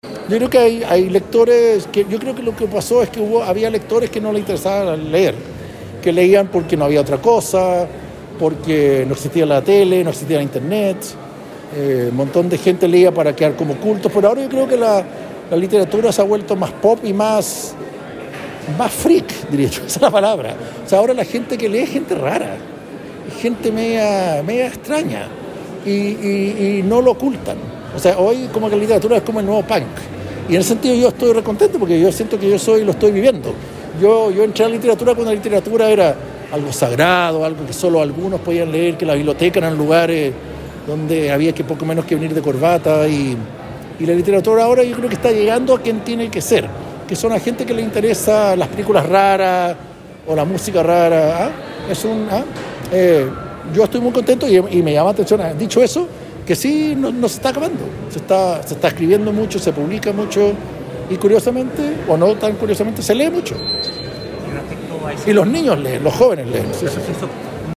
El escritor y cineasta participó en un conversatorio abierto a la comunidad en la Biblioteca Central